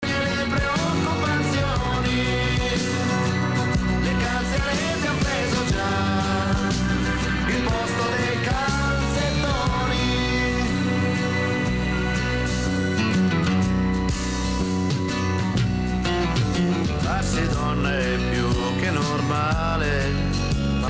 FM-тюнер
Тюнер продемонстрировал хорошее качество приёма в этом режиме.
Разумеется, о каком-либо качестве звука говорить сложно, для фонового прослушивания годится, но не более того (скачать
• Монофонический звук в FM-режиме